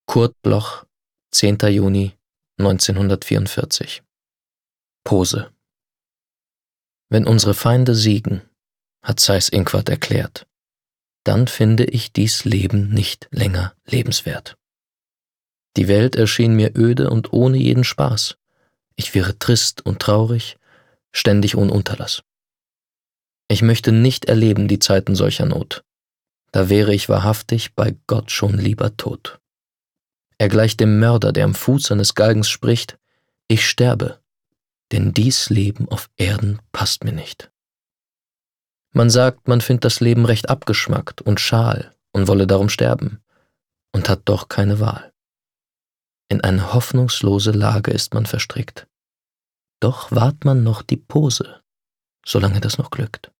Recording: speak low, Berlin · Editing: Kristen & Schmidt, Wiesbaden